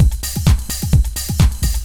Jive Beat 2_129.wav